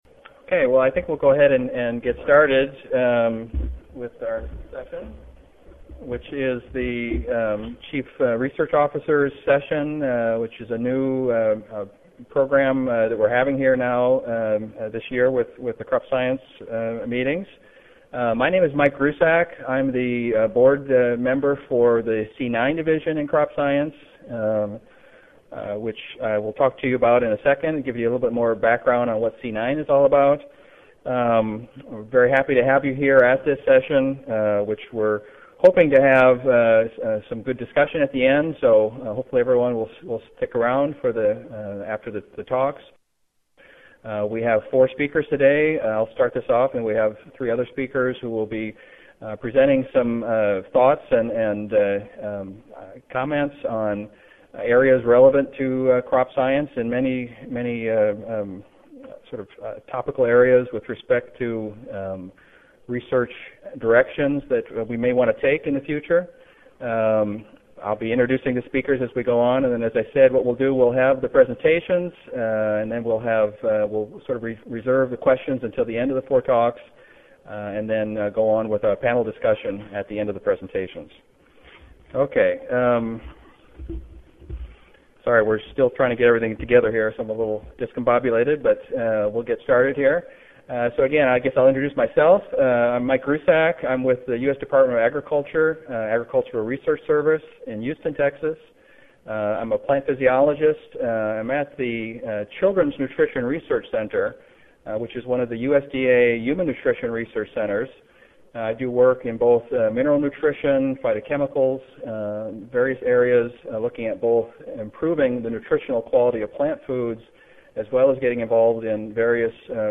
Z01 Z Series Special Sessions Session: Research Officers Panel Discussion (2010 Annual Meeting (Oct. 31 - Nov. 3, 2010))
Baylor College of Medicine Audio File Recorded presentation